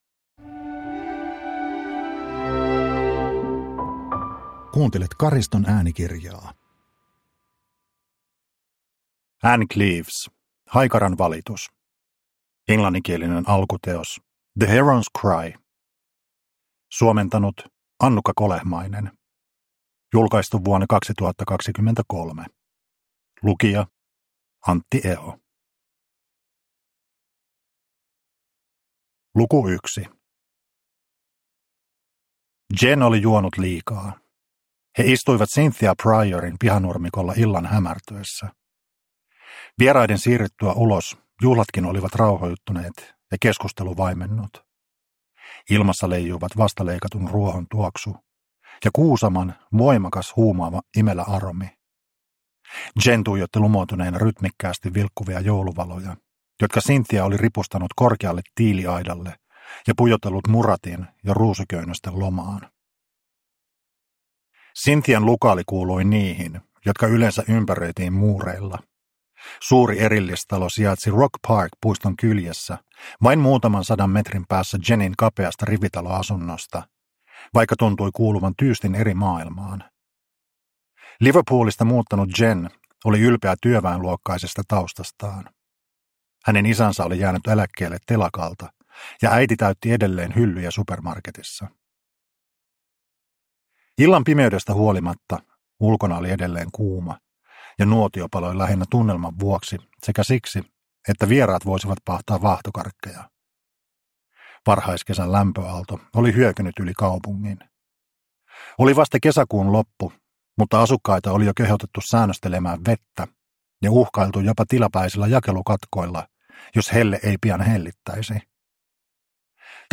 Haikaran valitus – Ljudbok – Laddas ner